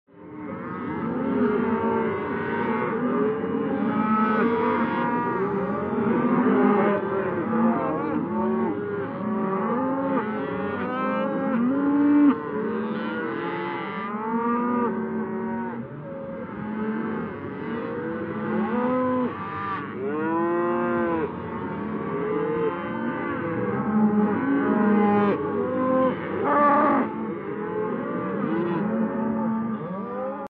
На этой странице собраны звуки коровьего колокольчика — натуральные и атмосферные записи, которые перенесут вас на деревенское пастбище.
Звуки фермы и коровника